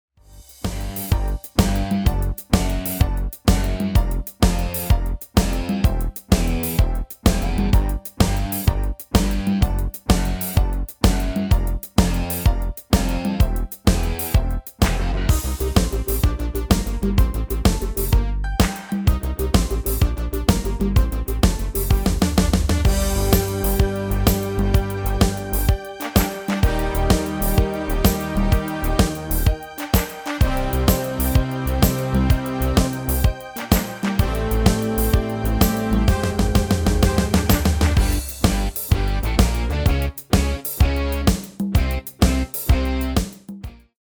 MP3 BackingTrack Euro 4.75
Demo's played are recordings from our digital arrangements.
Offered products are MP3 audio backing tracks.